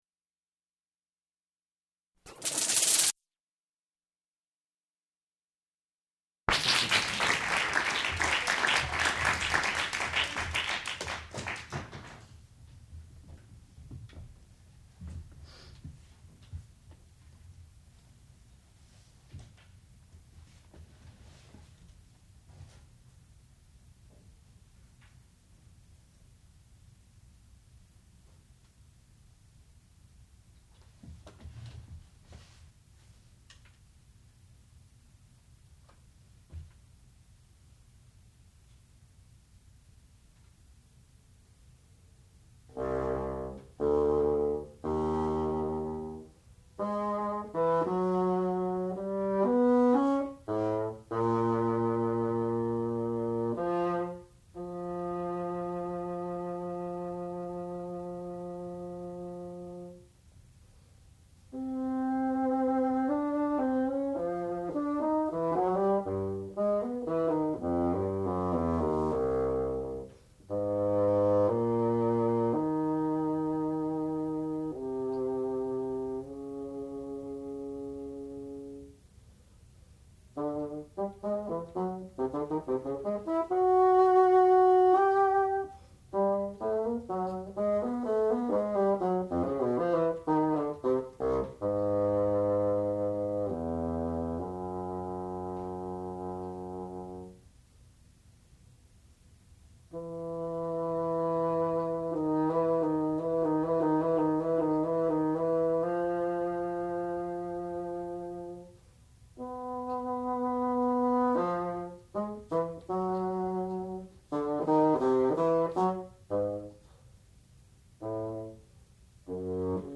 sound recording-musical
classical music
bassoon
Advanced Recital